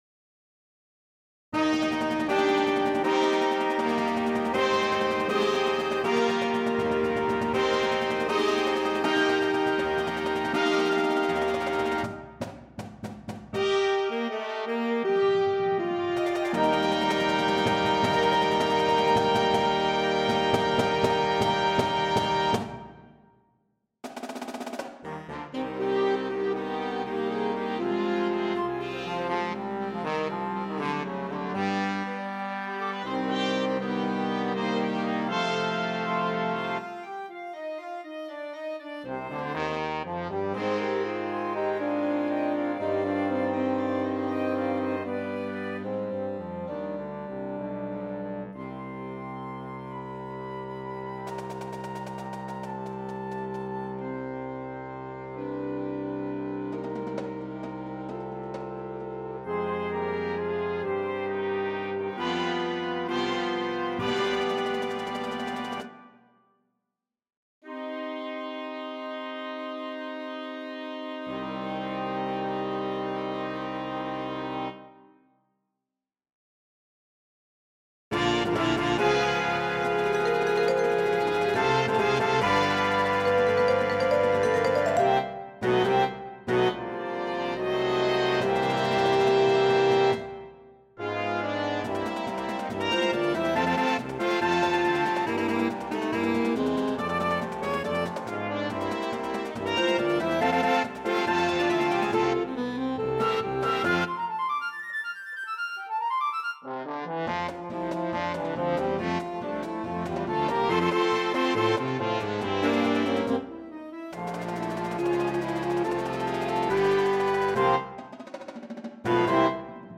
Winds